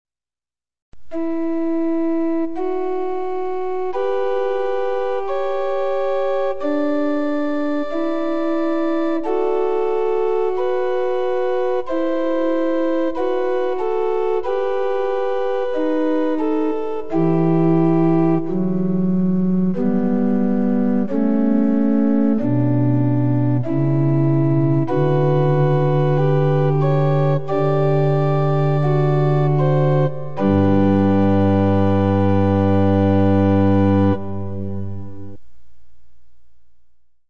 Orgue